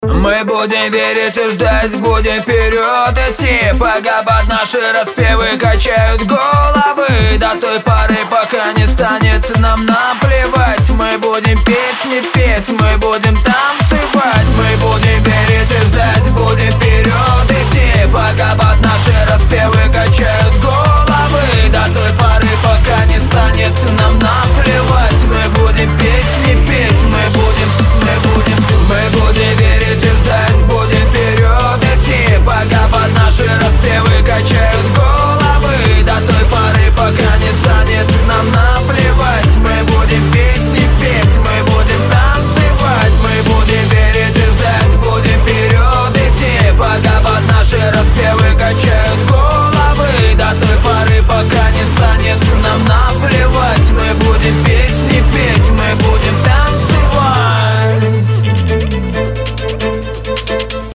• Качество: 320, Stereo
Хип-хоп
Trap
club
Rap
Хип-хоп, трэп и хаус в новой композиции